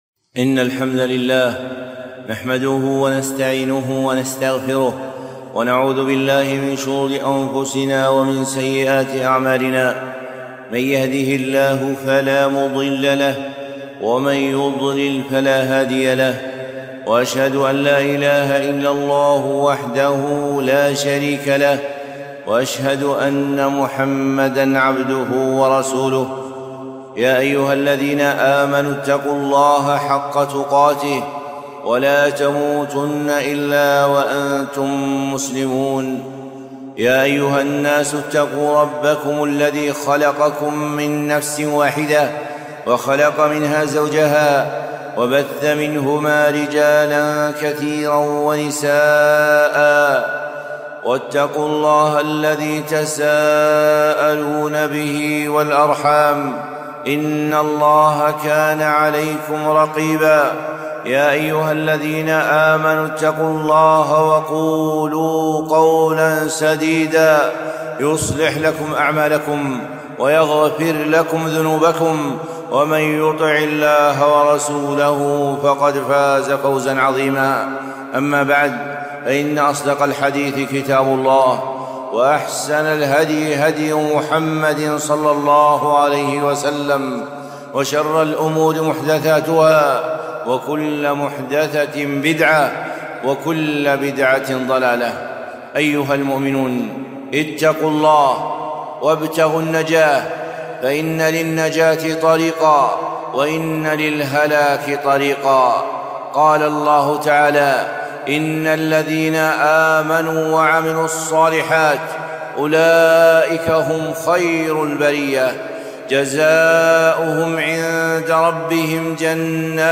خطبة - طريق نجاتنا